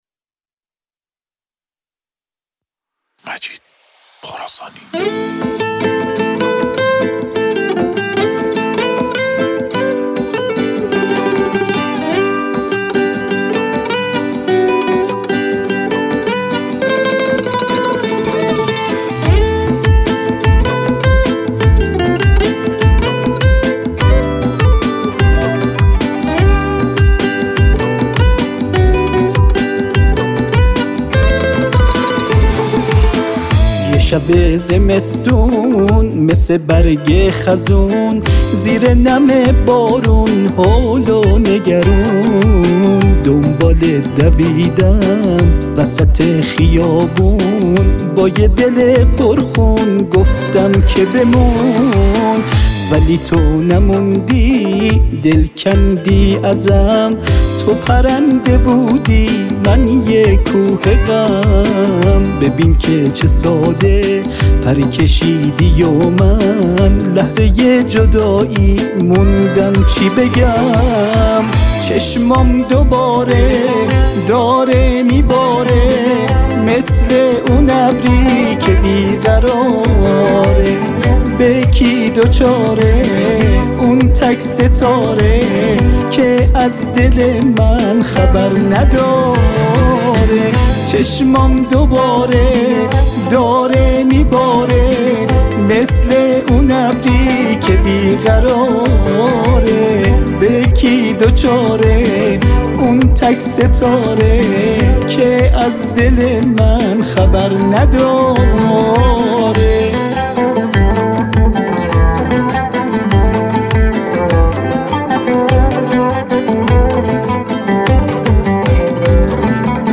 گیتار
عود